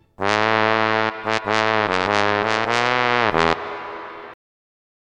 trombon.wav